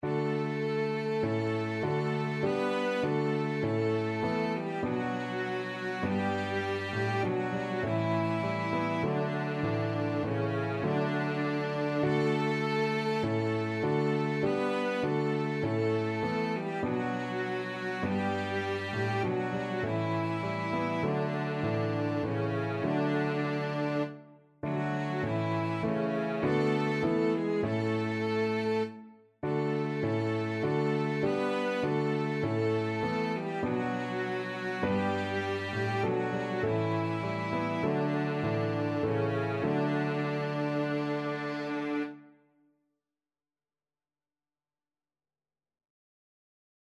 Christmas Christmas Flexible Unison Sheet Music Es ist ein Ros' entsprungen
4/4 (View more 4/4 Music)
D major (Sounding Pitch) (View more D major Music for Flexible Unison )
Moderato
Traditional (View more Traditional Flexible Unison Music)
Flexible Unison pieces feature a unison line, suitable for any instrument, plus piano accompaniment.